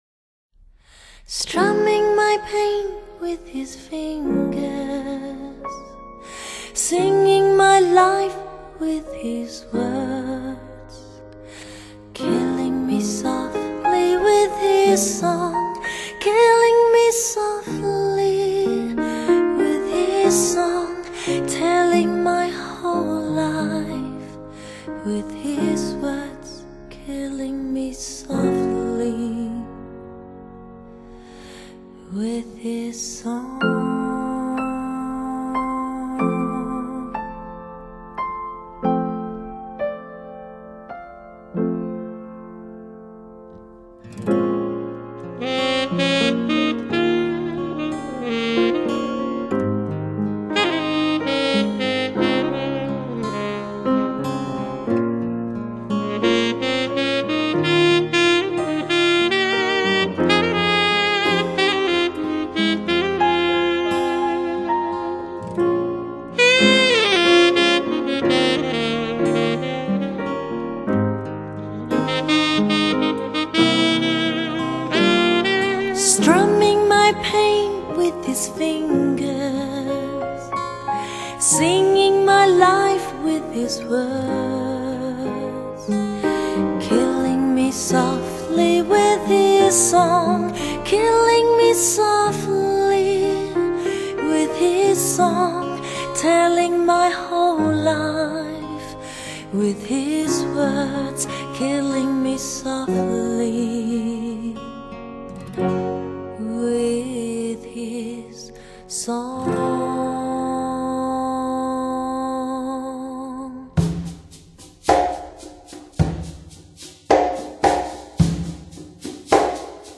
Genre: Vocal